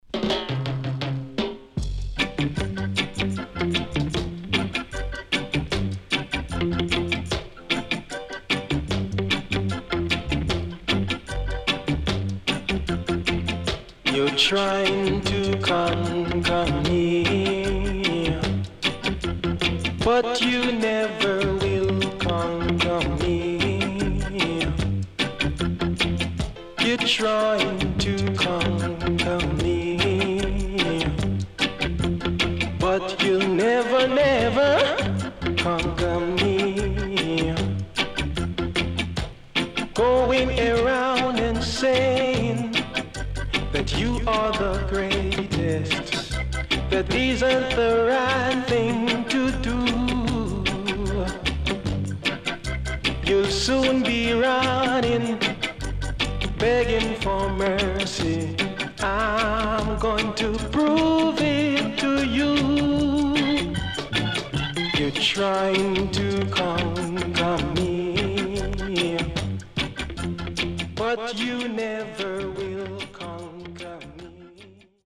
HOME > LP [VINTAGE]  >  SWEET REGGAE
SIDE A:少しノイズ入りますが良好です。
SIDE B:少しノイズ入りますが良好です。